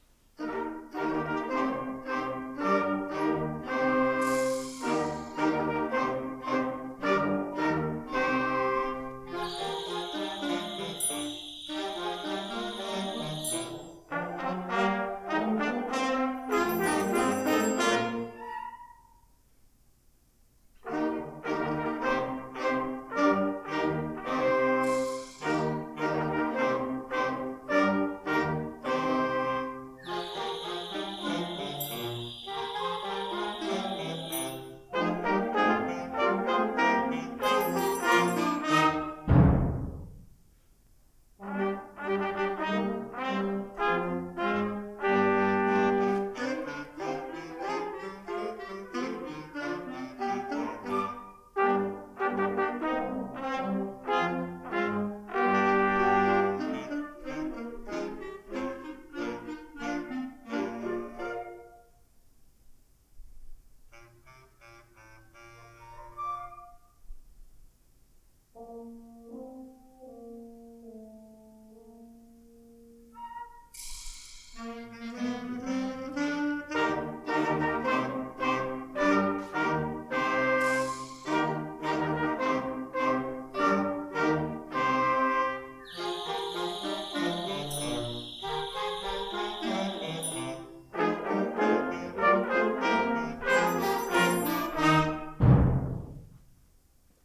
9-12 Grade Sr. High Band -